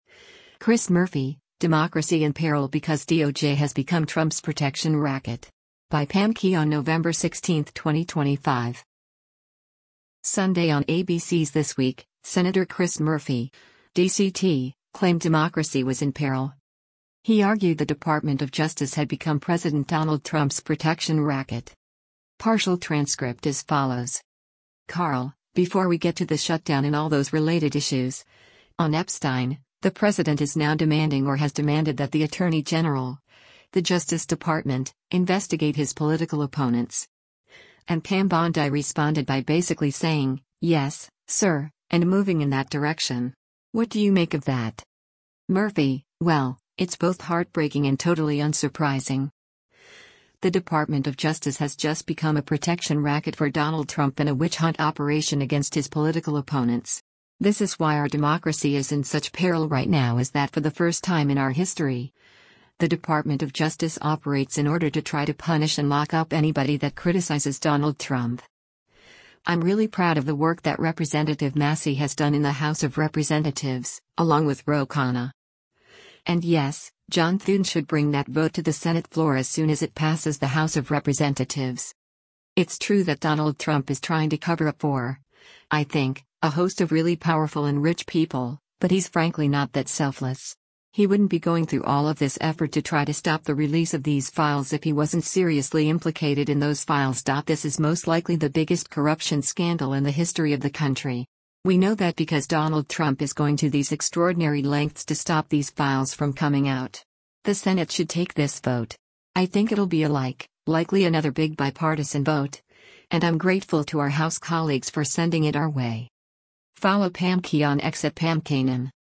Sunday on ABC’s “This Week,” Sen. Chris Murphy (D-CT) claimed democracy was “in peril.”